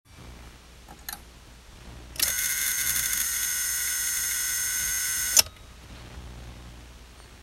téma: Rele blokovania startu - bzuci
Poradte, co moze blokovat start. Po otoceni kluca bzuci rele blokovania startu (kotva rele lieta horedole).